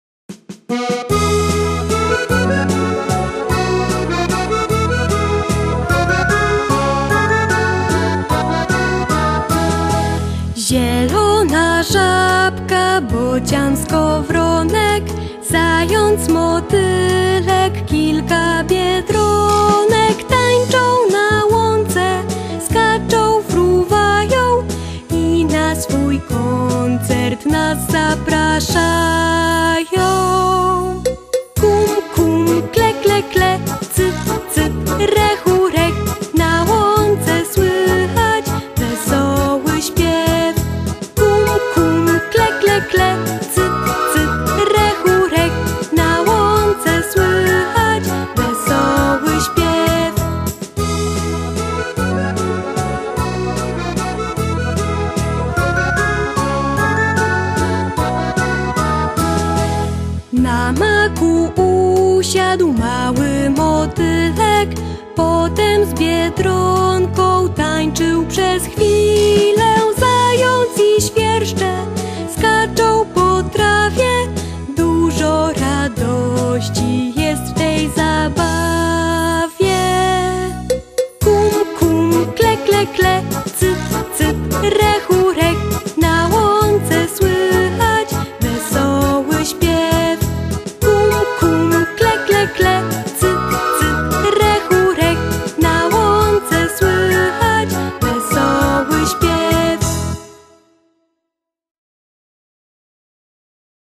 Utwór muzyczny -